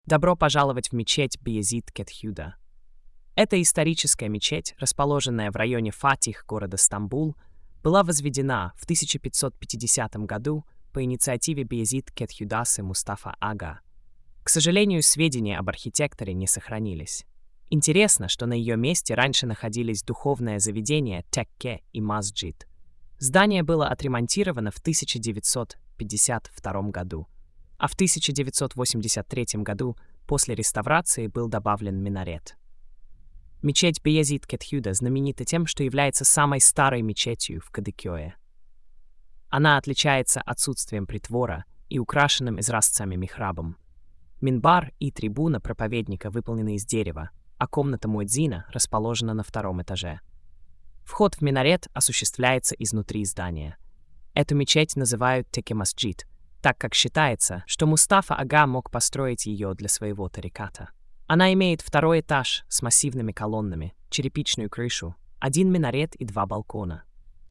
Аудиоповествование